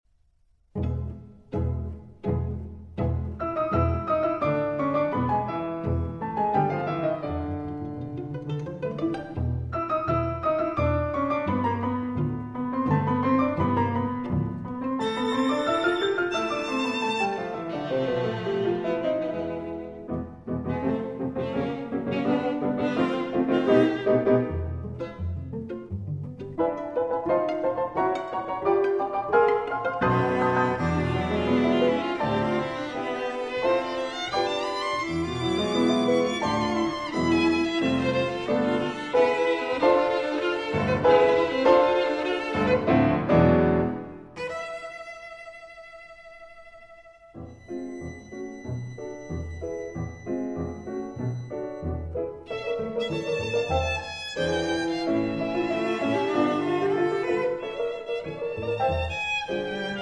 cello
violin
piano